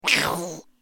دانلود آهنگ گربه ترسناک و عصبی از افکت صوتی انسان و موجودات زنده
جلوه های صوتی
دانلود صدای گربه ترسناک و عصبی از ساعد نیوز با لینک مستقیم و کیفیت بالا